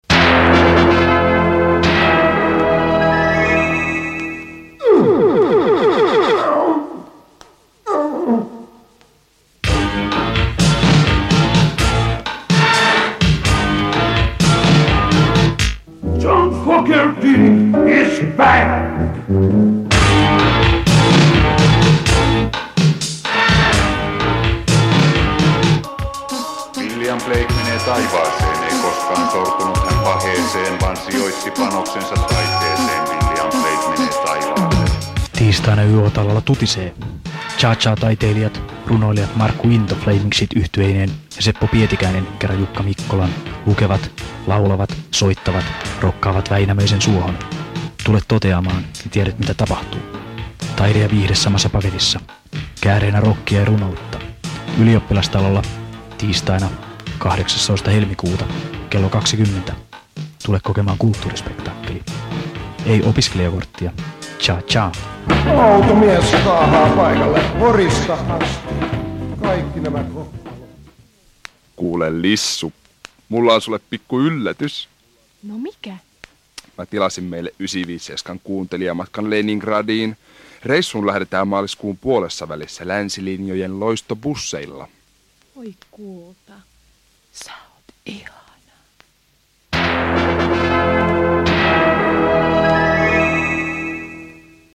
Radio 957:n mainoskatko helmikuussa 1986.